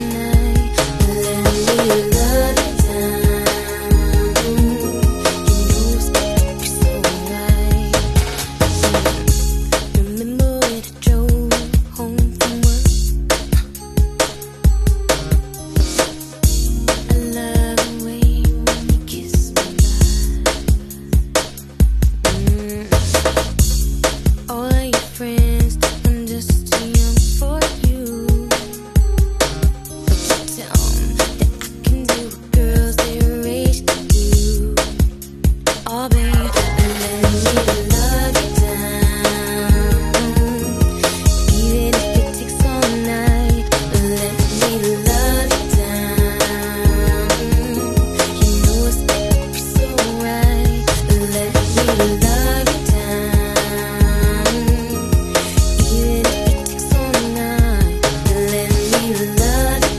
A '90s slow-jam cover that hit just right
And that soft, whispery vocal?